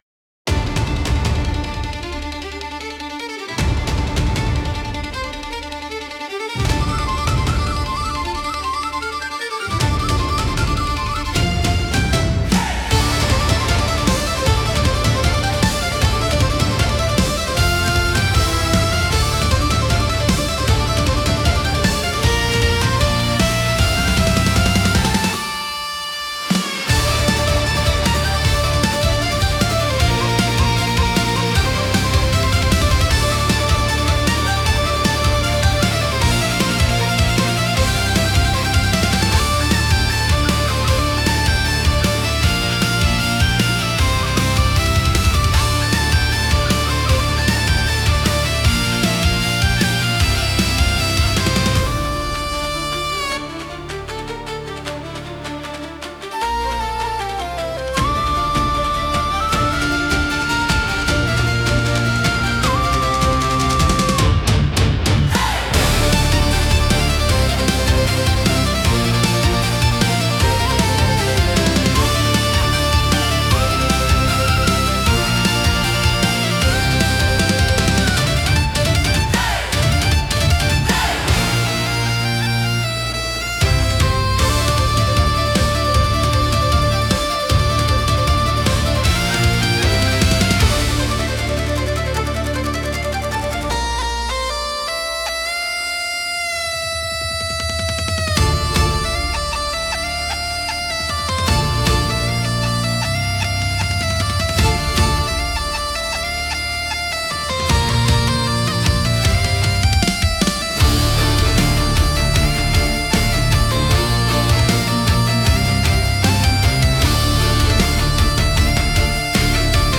Instrumental / 歌なし
その名の通り、戦場を切り裂く一筋の光のような、疾走感と野生味あふれる「ケルティック・アクション・オーケストラ」です。
速いテンポで刻まれる弦楽器と、身体の芯に響く太鼓のリズムが、聴く人の血を騒がせます。
そこにフィドルや笛といった民族楽器の音色が加わり、まるでファンタジー映画の主人公になったかのような英雄的な気分に！